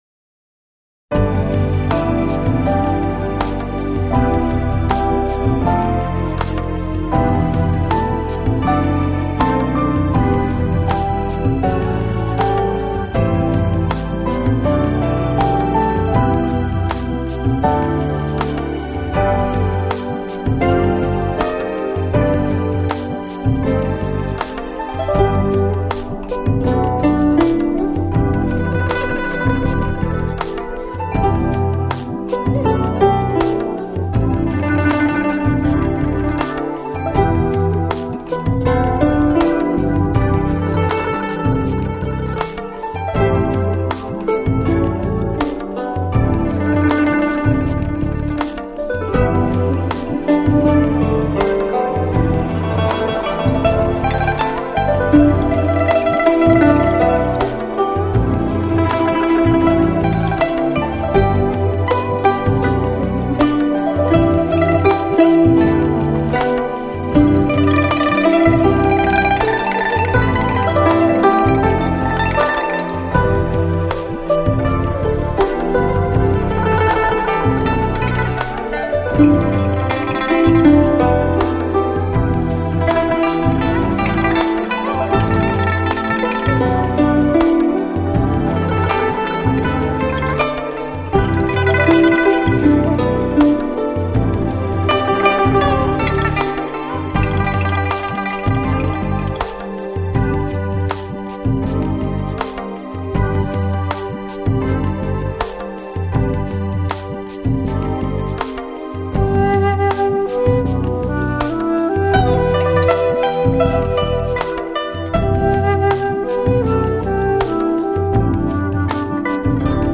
古箏
三弦